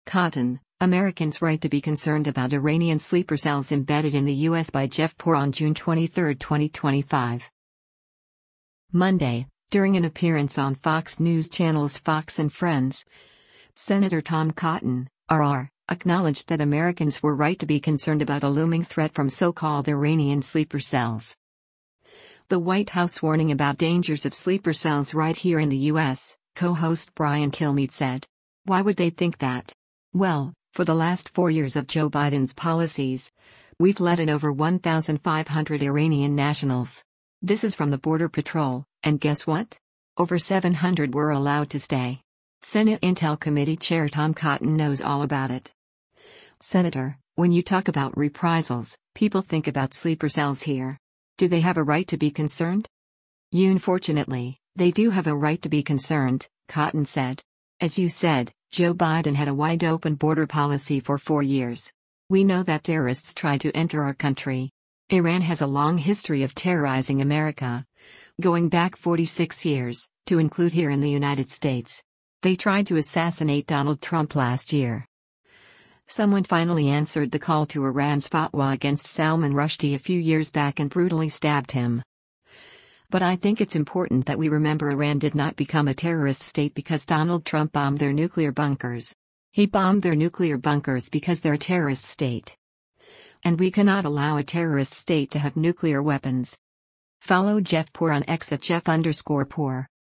Monday, during an appearance on Fox News Channel’s “Fox & Friends,” Sen. Tom Cotton (R-AR) acknowledged that Americans were “right to be concerned” about a looming threat from so-called Iranian sleeper cells.